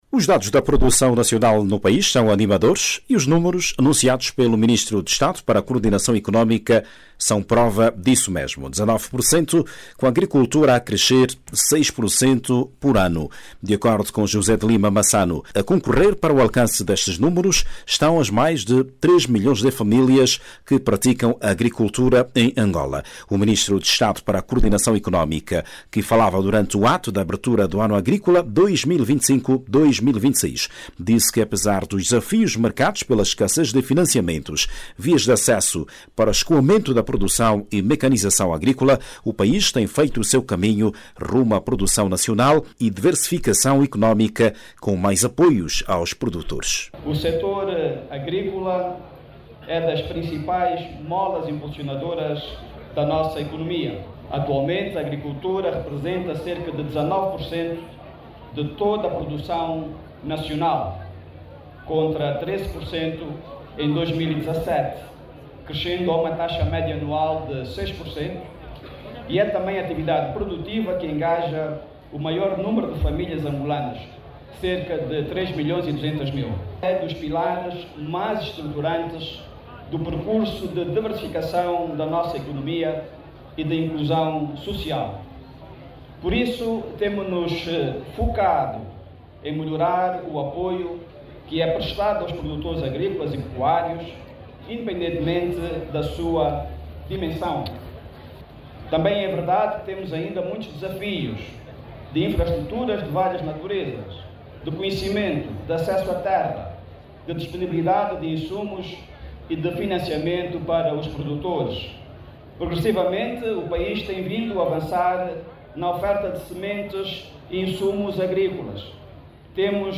O sector da agricultura cresce anualmente 6 por cento sendo um dos pilares que impulsionam a diversificação da economia angolana e inclusão social. Os dados, foi apresentado pelo Ministro de Estado para Coordenação Económica, José de Lima Massano na cerimónia que marcou a abertura do ano agrícola na Província do Moxico. Por outro lado, José de Lima Massano falou também dos desafios enfrentados para se alcançar uma agricultura robusta em Angola.